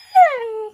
Wolf Tamed Minecraft Efecto de Sonido Descargar
Games Soundboard1 views